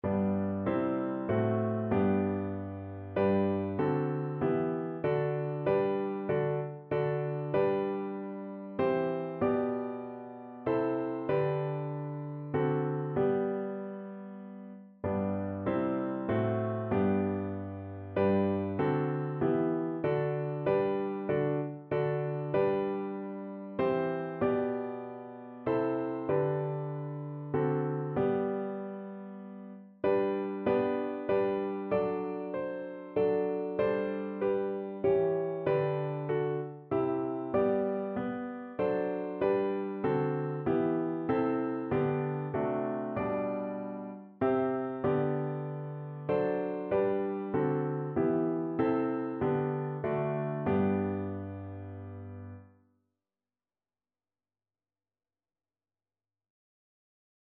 Notensatz 1 (4 Stimmen gemischt)
• gemischter Chor mit Akk.